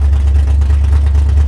dukes_idle.wav